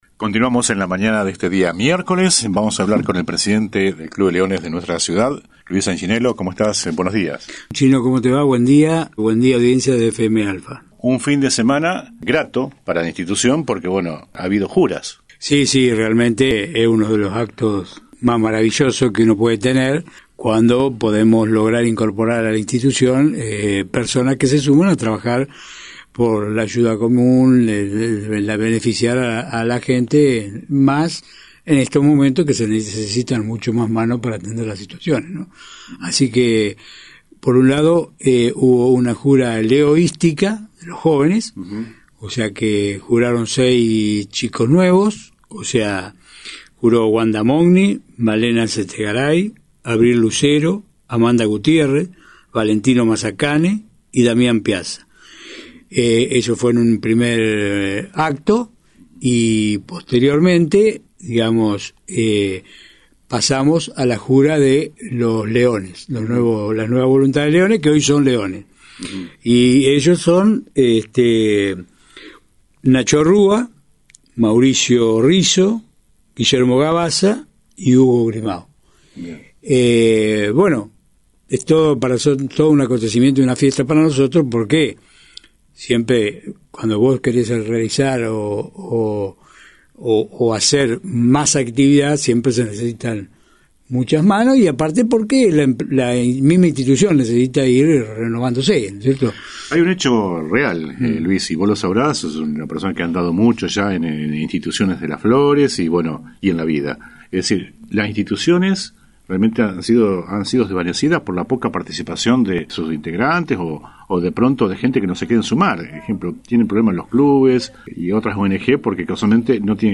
ENTREVISTA COMPLETEA